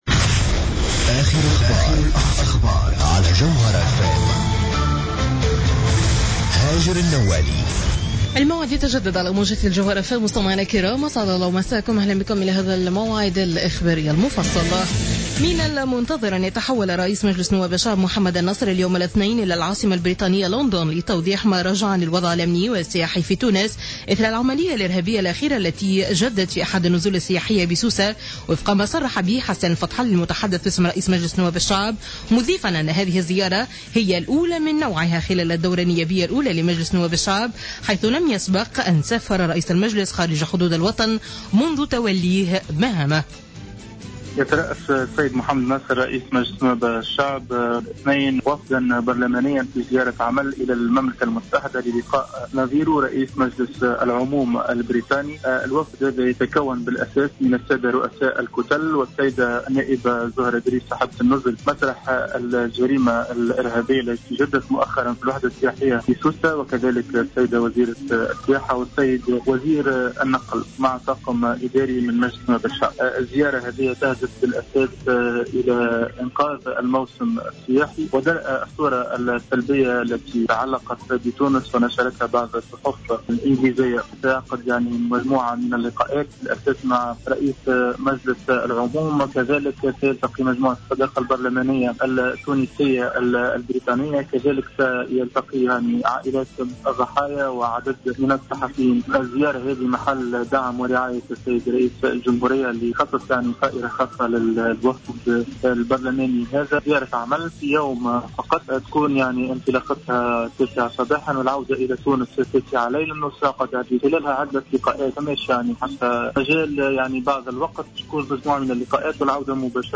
نشرة أخبار منتصف الليل ليوم الإثنين 20 جويلية 2015